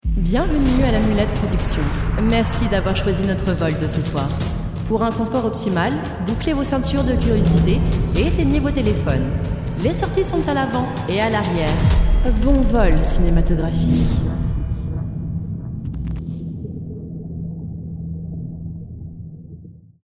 Voix off
25 - 45 ans - Mezzo-soprano